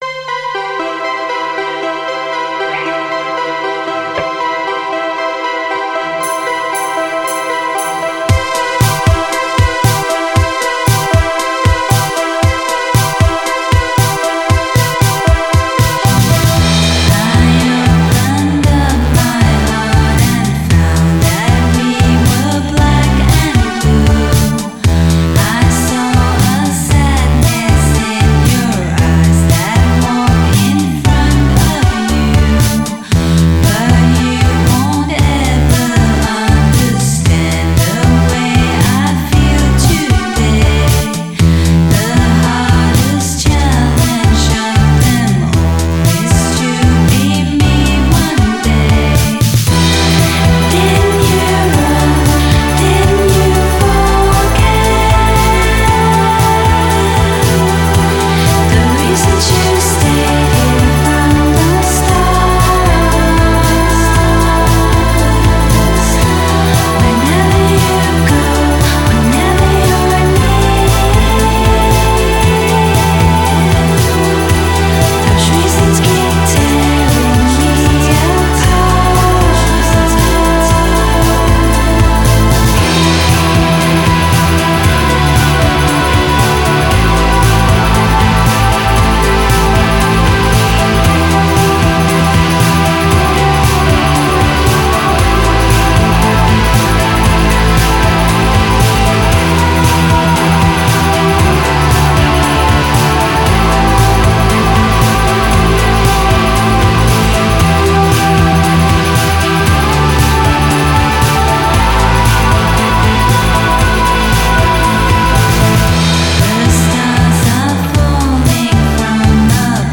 Synthiepopband